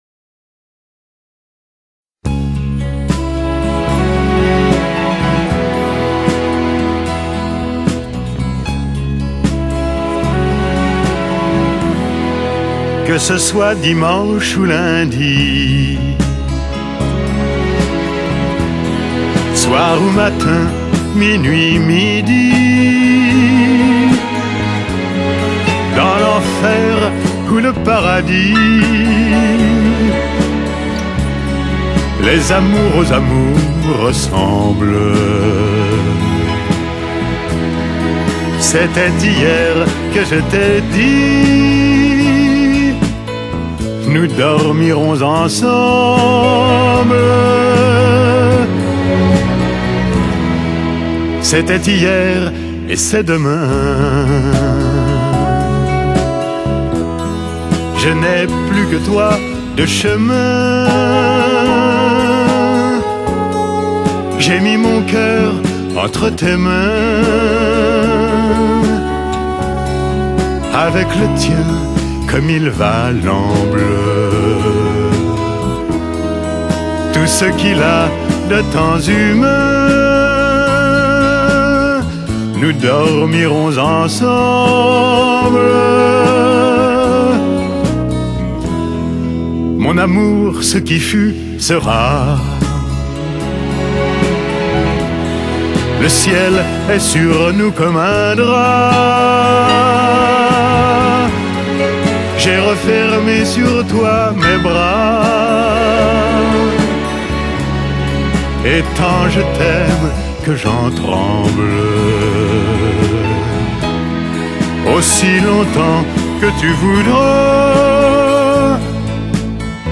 Ce sont de beaux vers en effet et une voix d’un autre temps.